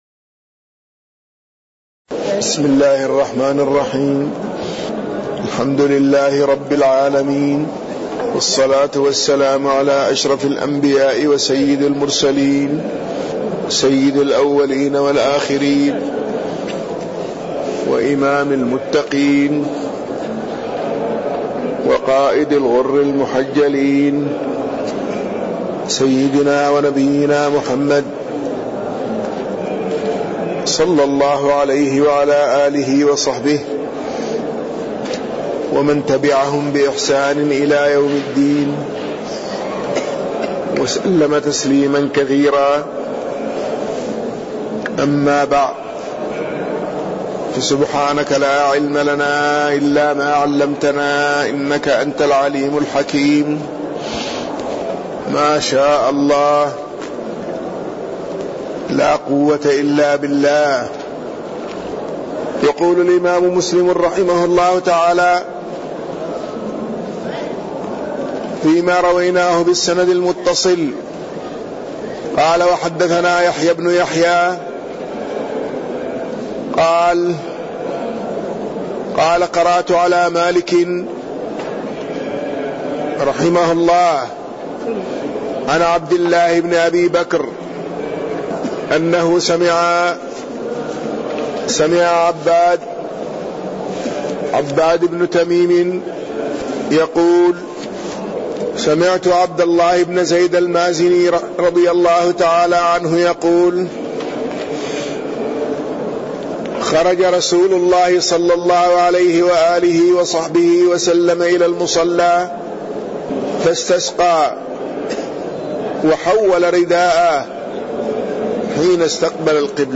تاريخ النشر ٢٠ شوال ١٤٣١ هـ المكان: المسجد النبوي الشيخ